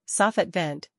saa · fuht - vent